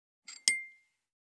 243,食器,グラス,コップ,工具,小物,雑貨,コトン,トン,ゴト,ポン,ガシャン,ドスン,ストン,カチ,タン,バタン,スッ,サッ,コン,ペタ,パタ,チョン,コス,カラン,ドン,チャリン,効果音,
コップ効果音厨房/台所/レストラン/kitchen物を置く食器